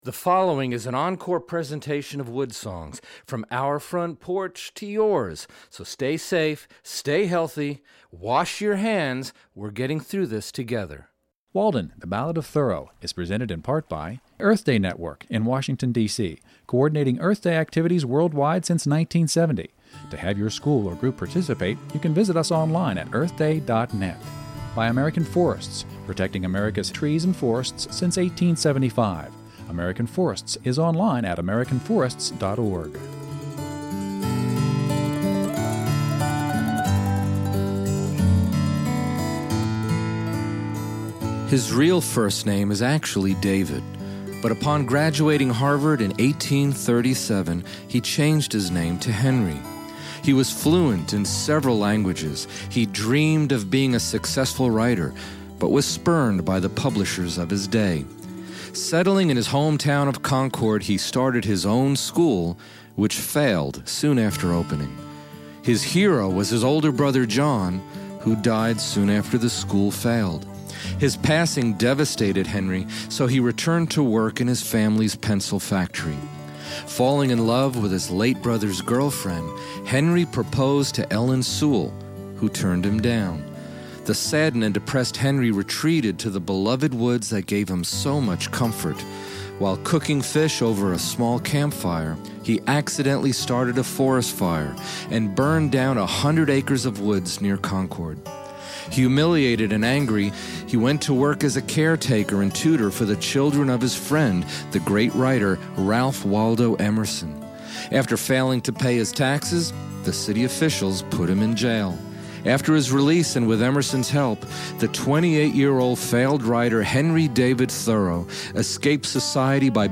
The WoodSongs Old-Time Radio Hour, is an hour of non-comm, live audience celebration of the worldwide musical front porch ... performance and conversation with brilliant musical artists from around the globe.